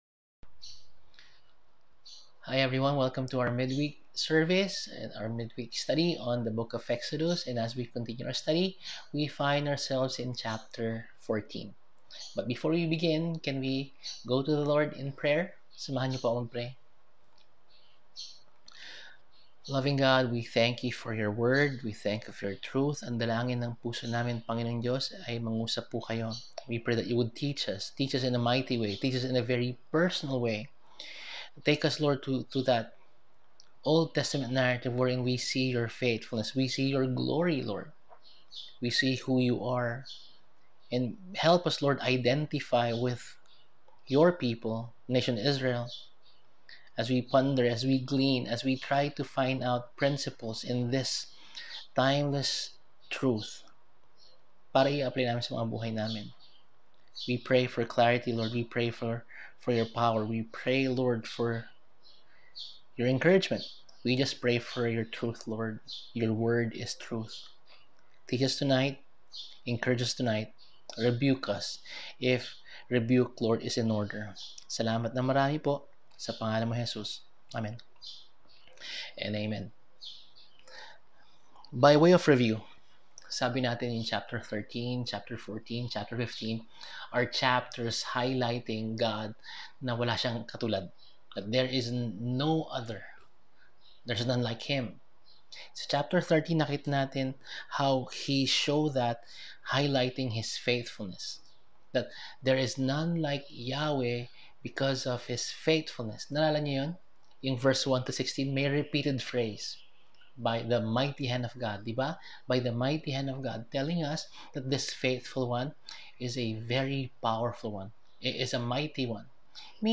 Service: Midweek